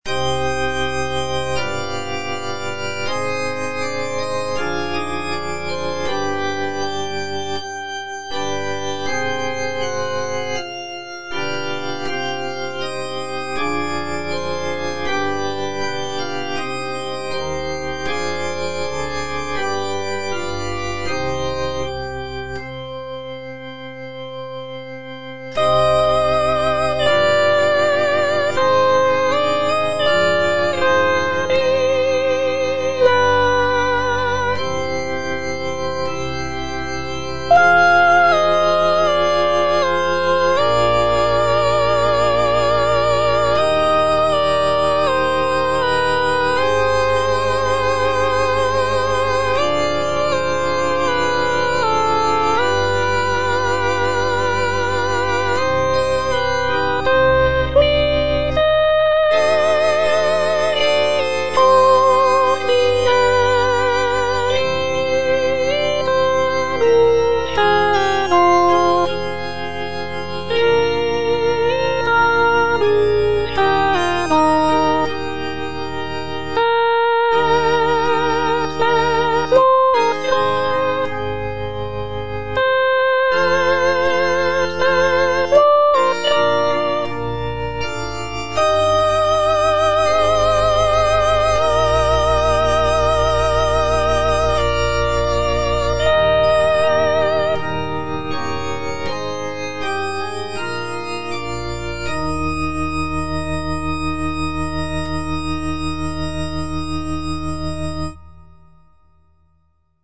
G.B. PERGOLESI - SALVE REGINA IN C MINOR Salve Regina - Soprano (Voice with metronome) Ads stop: auto-stop
"Salve Regina in C minor" is a sacred choral work composed by Giovanni Battista Pergolesi in the early 18th century. It is a setting of the traditional Marian antiphon "Salve Regina" and is known for its poignant and expressive melodies.